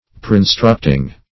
Preinstructing - definition of Preinstructing - synonyms, pronunciation, spelling from Free Dictionary
preinstructing.mp3